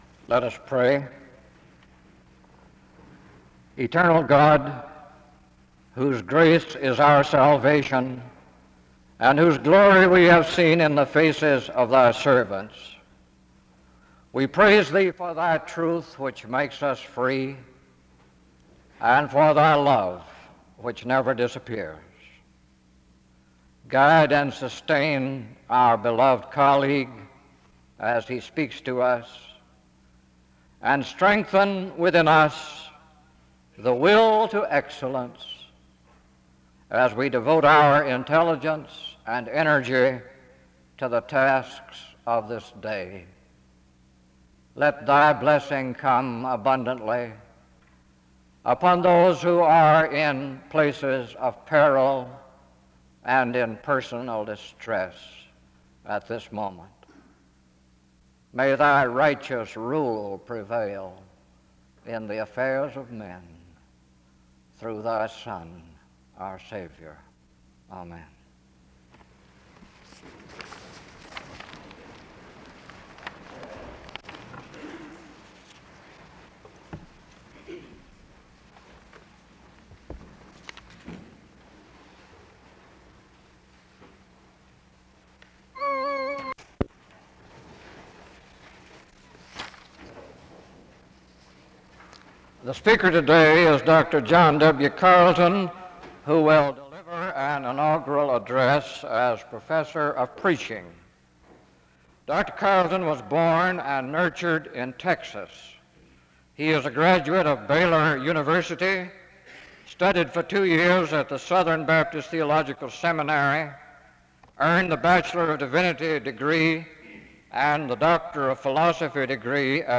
The service begins with a prayer (0:00-1:09).
He closes with benediction (45:34-46:01). Chapel is distorted from 38:29-38:53.